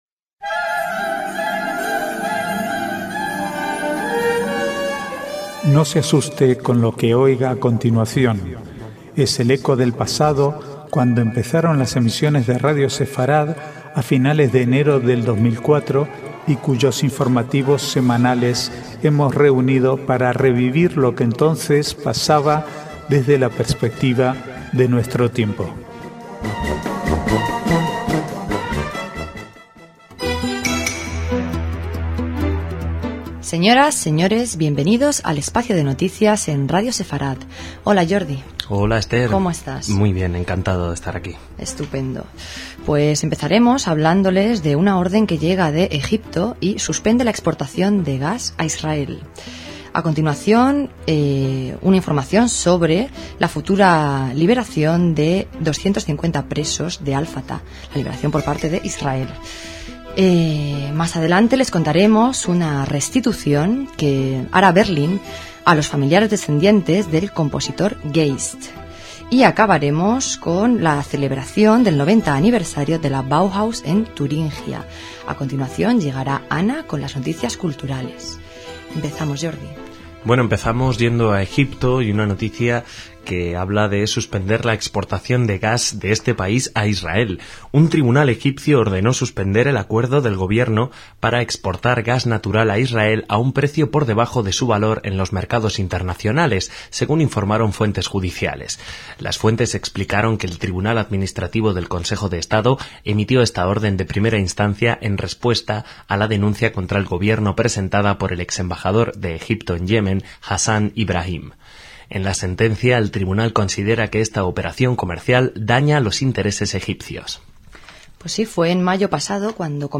Actualidad Judía Archivo de noticias del 19 al 21/11/2008 Sep 11 2023 | 00:43:39 1x 00:00 / 00:43:39 Subscribe Share RSS Feed Share Link Embed